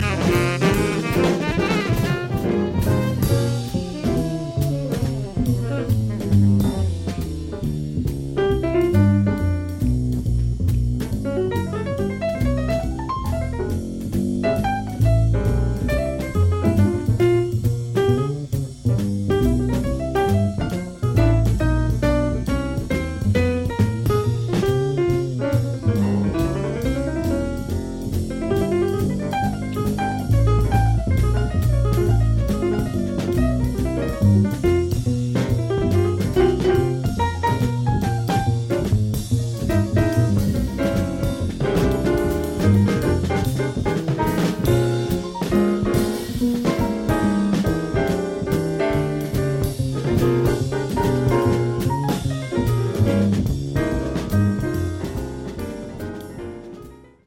So you’re awash in a sea of melodic jazz until this: